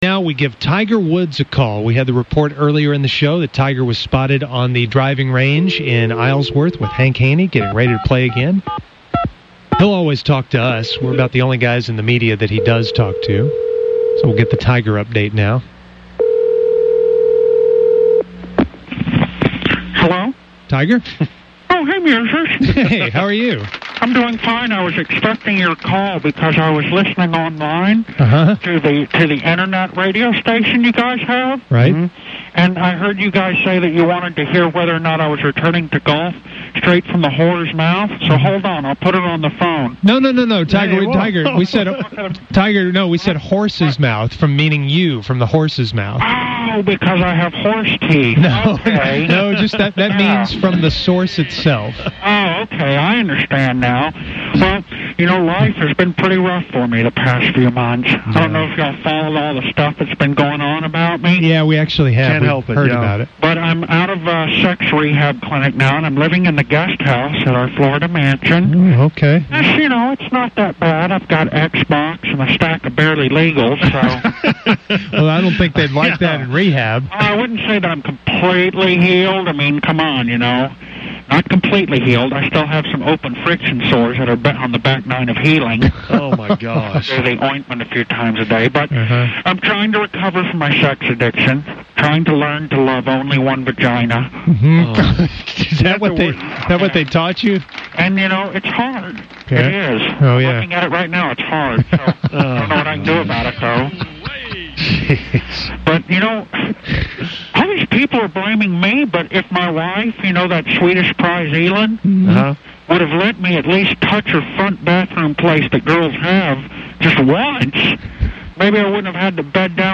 Fake Tiger Woods - Back to Golf?
fake-tiger-comeback.mp3